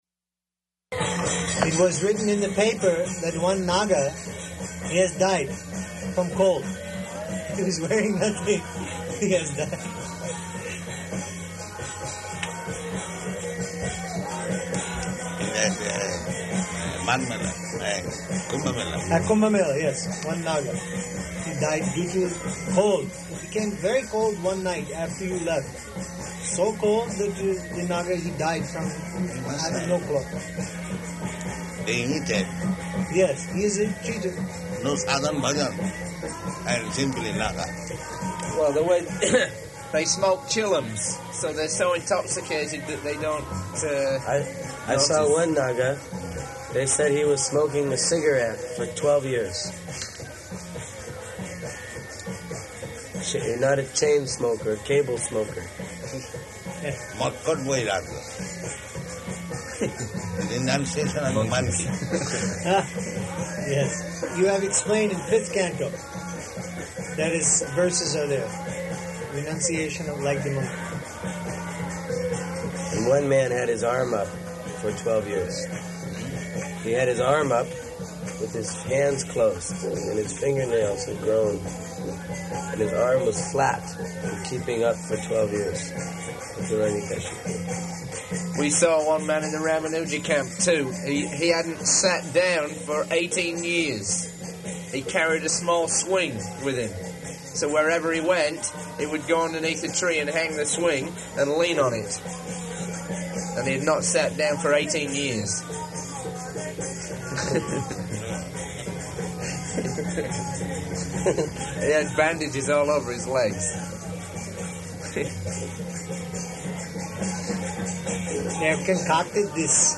Room Conversation
Type: Conversation
Location: Bhubaneswar
[ kīrtana in background]